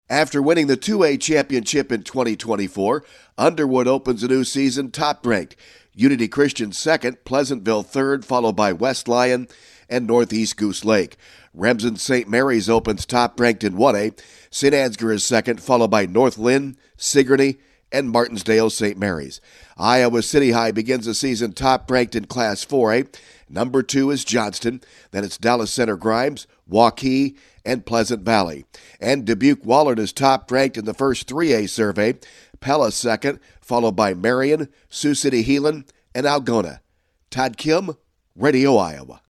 Reports.